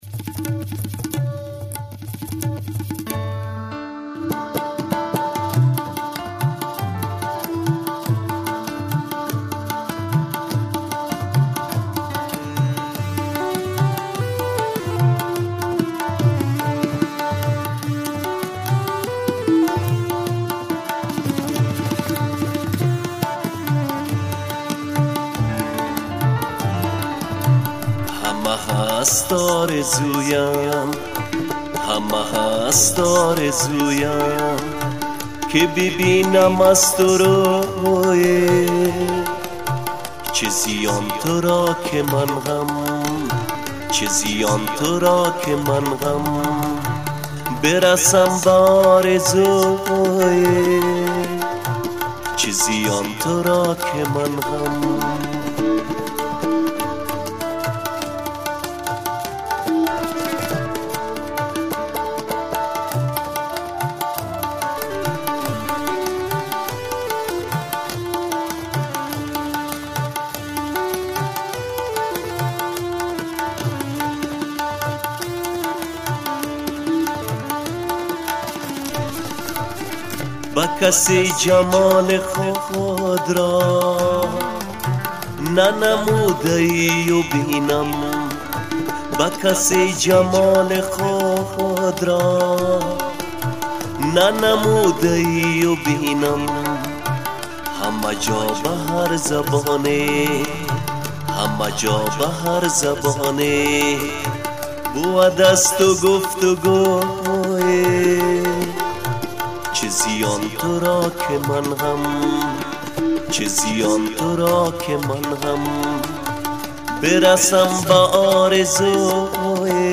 хонандаи афғонистонӣ